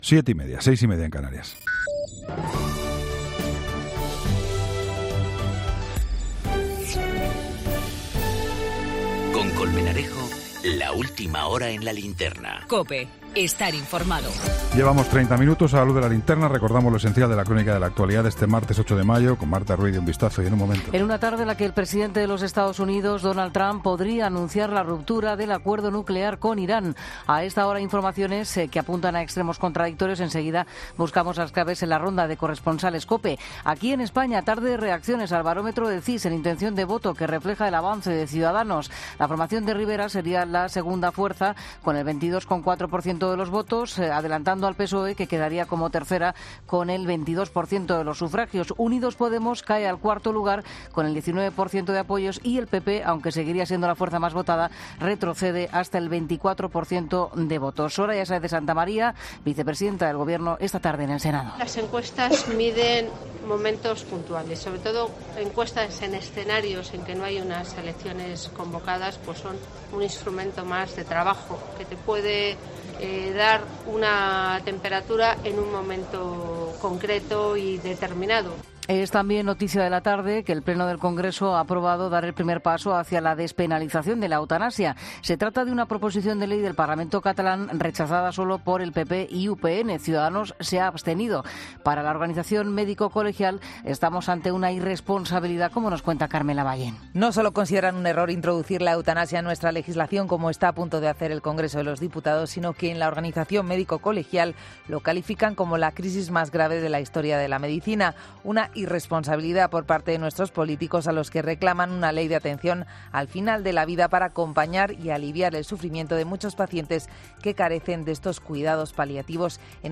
Ronda de corresponsales.
Noticias...